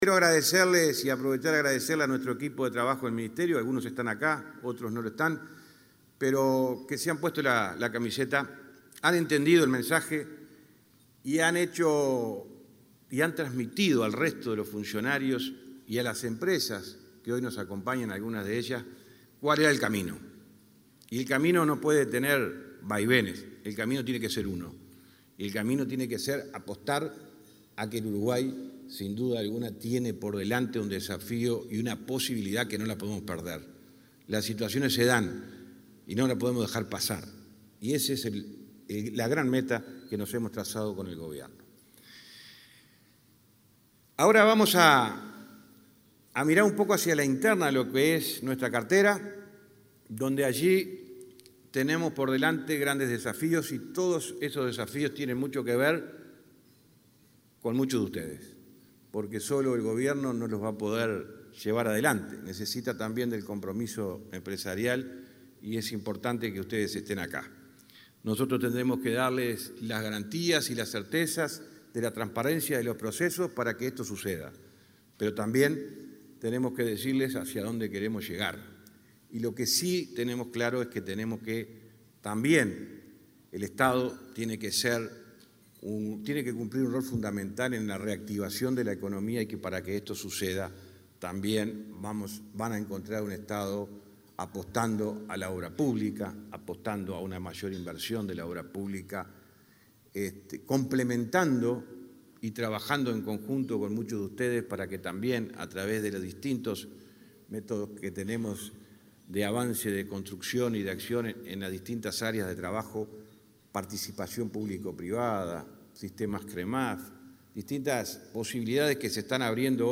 Disertación del ministro de Transporte, José Luis Falero
El ministro de Transporte, José Luis Falero, disertó en un almuerzo de trabajo de la Asociación de Dirigentes de Marketing, realizado este jueves 25